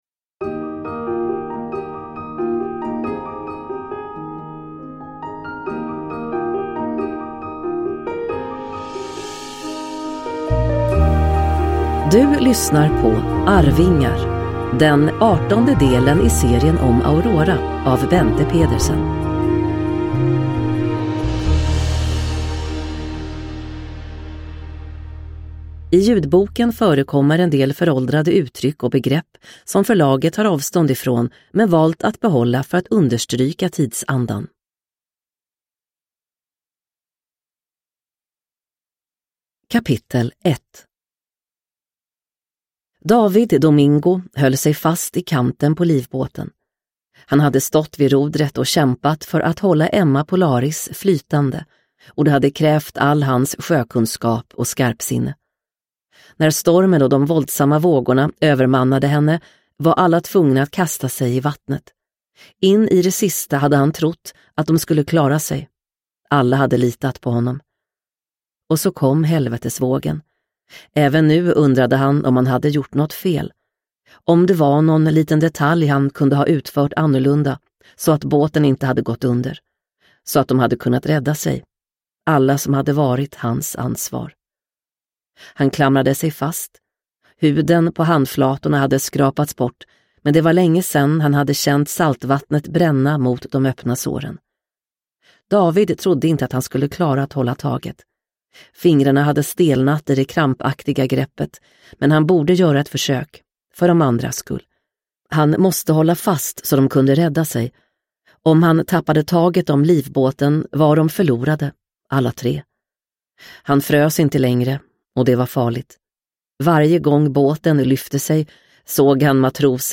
Arvingar – Ljudbok – Laddas ner